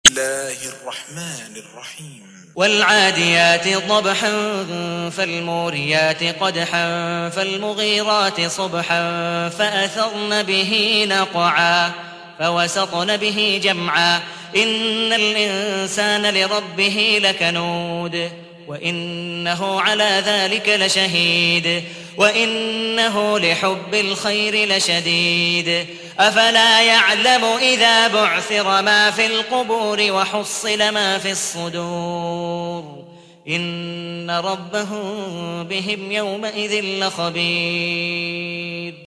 تحميل : 100. سورة العاديات / القارئ عبد الودود مقبول حنيف / القرآن الكريم / موقع يا حسين